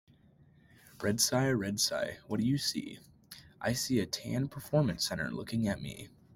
A repetitive, rhythmic chant follows Red Cy as it spots different colored campus sights, ending with the Cyclones seeing them all looking back.